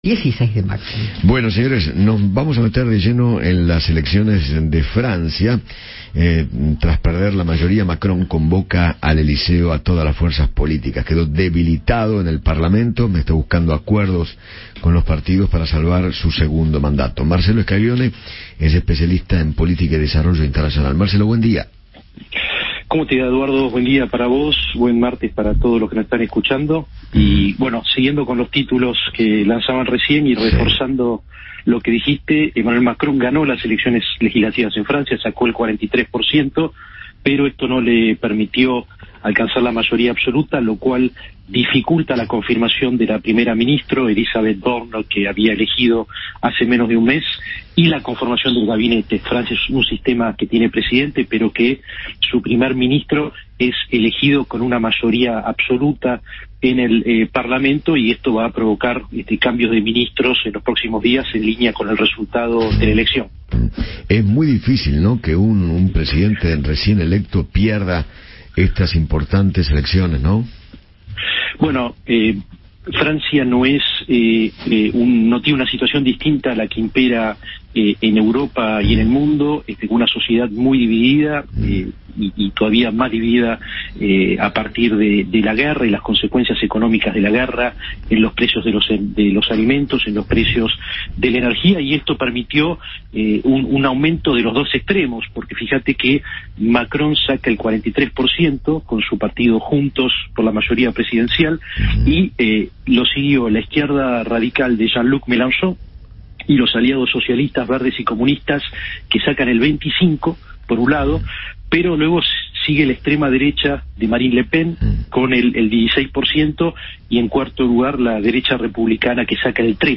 especialista en política internacional